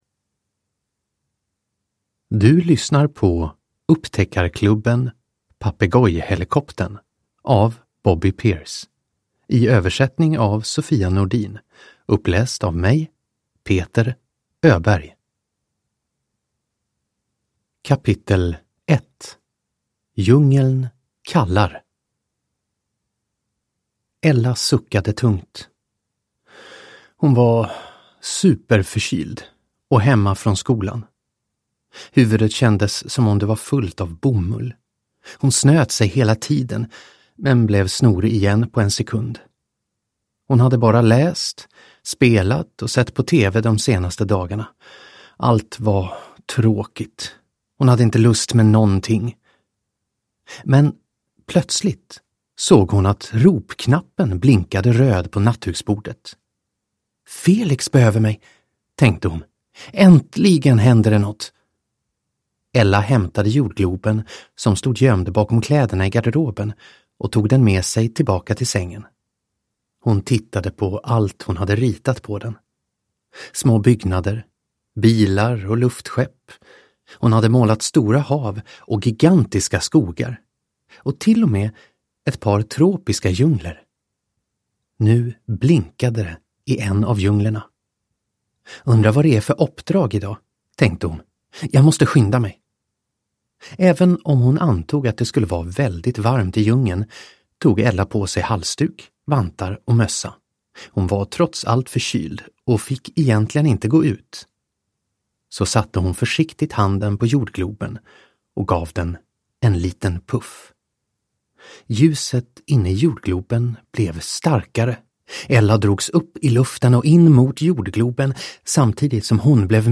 Papegojhelikoptern – Ljudbok – Laddas ner